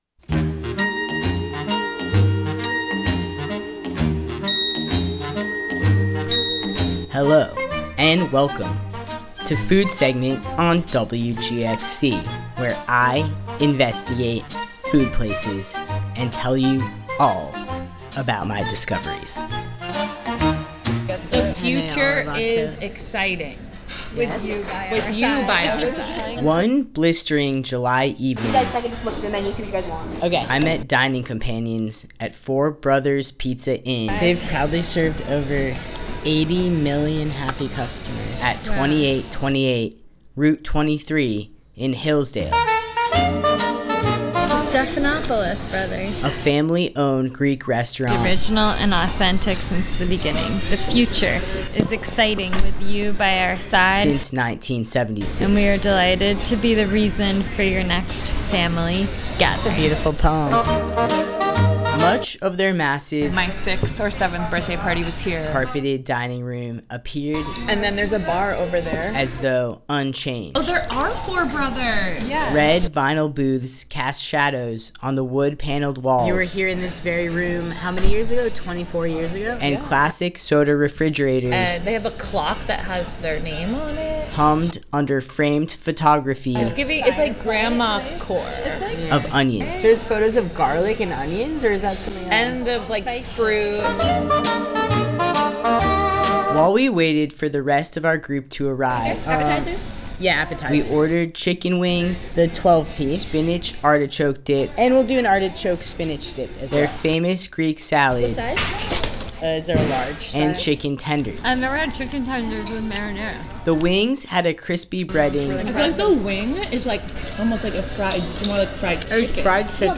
The Food Segment participants meet at Four Brothers Pizza Inn at 2828 Rt. 23 in Hillsdale to indulge on appetizers, pizzas, and their famous Greek salad.